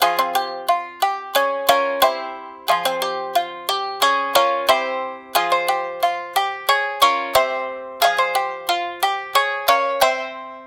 На этой странице собраны звуки сямисэна — уникального трёхструнного инструмента, популярного в японской музыке.
Сямисэн – Вслушайтесь